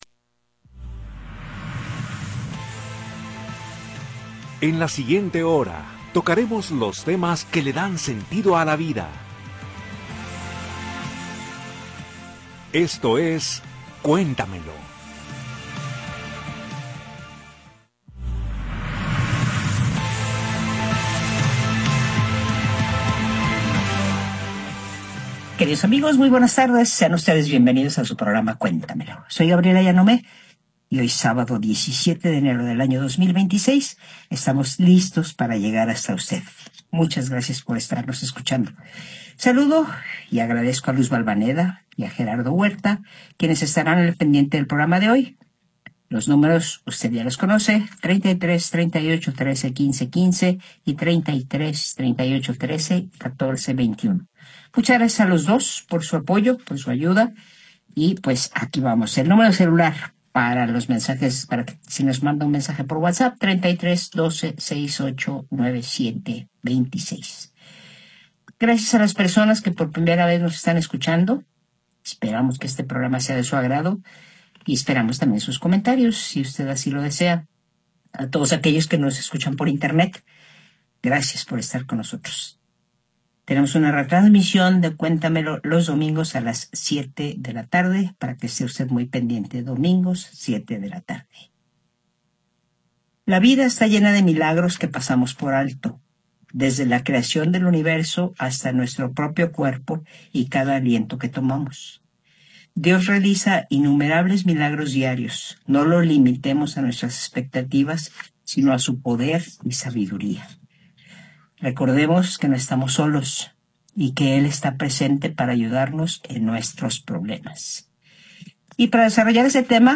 Programa transmitido el 17 de Enero de 2026.